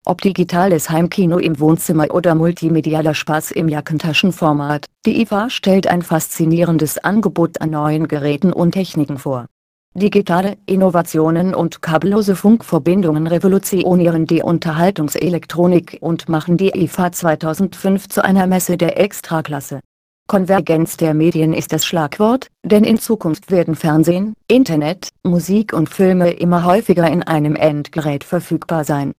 Texte de d�monstration
Nuance RealSpeak; distribu� sur le site de Nextup Technology; femme; allemand